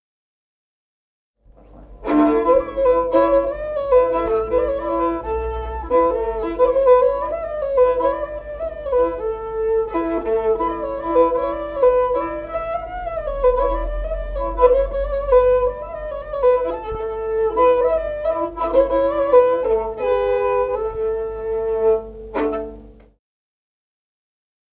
Музыкальный фольклор села Мишковка «Ой, рано-рано», репертуар скрипача.